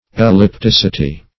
Ellipticity \El`lip*tic"i*ty\, n. [Cf. F. ellipticit['e].]